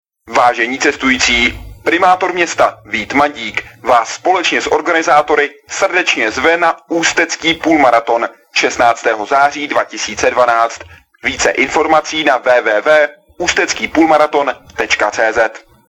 - Hlášení o pořádaném maratonu si
V samotném hlášení, které bude v trolejbusech znít do ukončení denního provozu v neděli 16. září, pak na maraton zve sám primátor města Ústí nad Labem Vít Mandík, leč hlasem kohosi jiného.